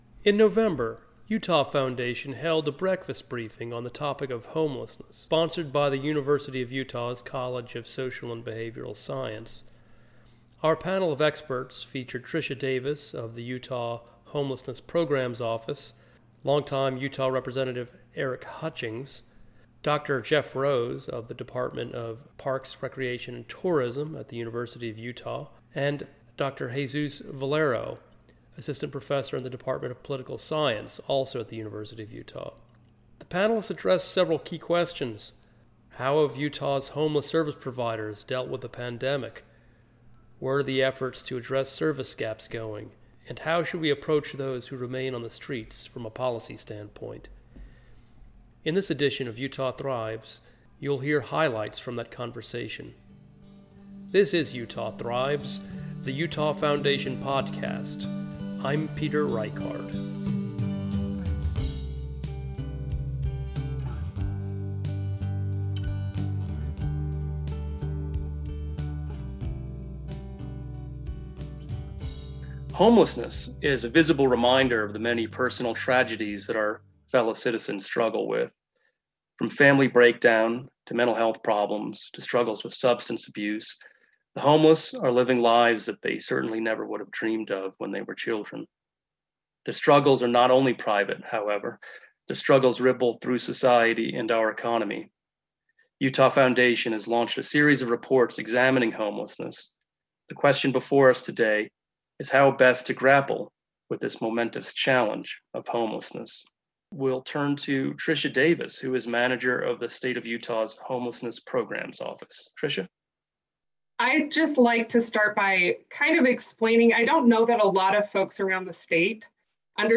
In November, Utah Foundation held a Breakfast Briefing on the topic of homelessness, sponsored by the University of Utah’s College of Social and Behavioral Science. Our panel of experts featured: